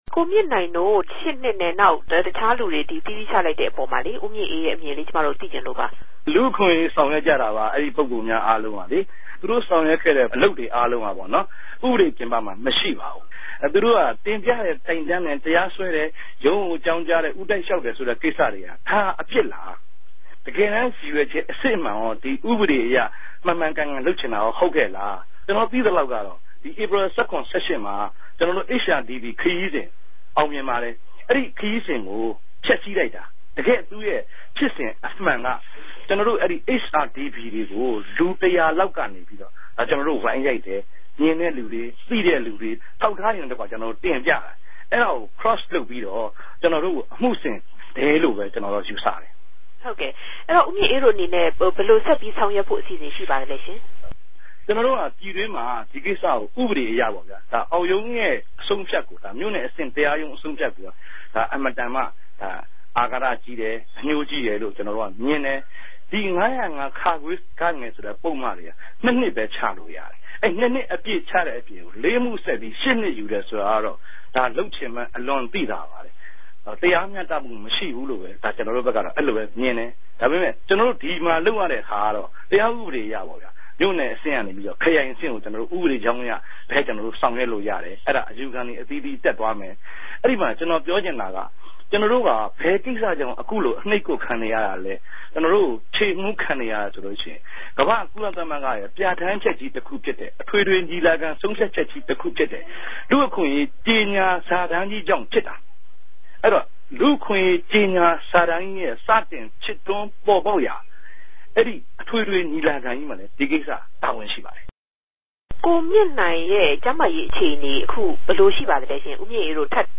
လူႛအခြင့်အရေး လြပ်ရြားသူမဵားအား ထောင်ဒဏ်ခဵမြတ်ူခင်းအပေၞ ဆက်သြယ်မေးူမန်းခဵက်